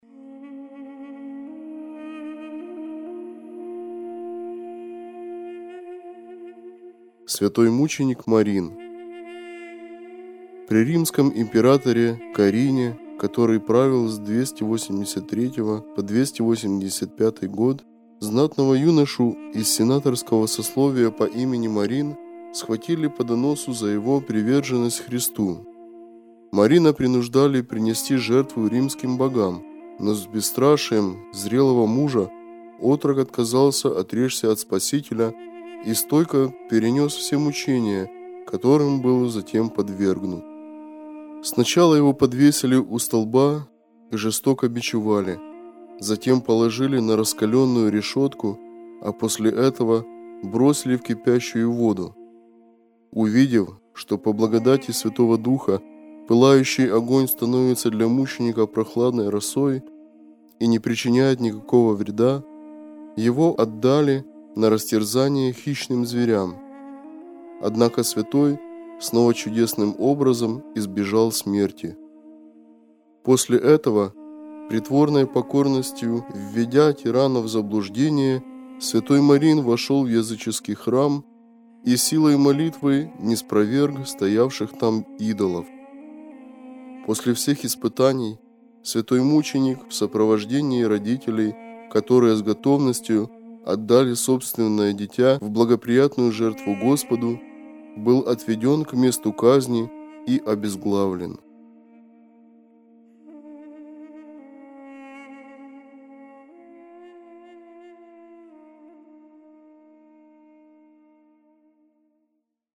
Читает иеромонах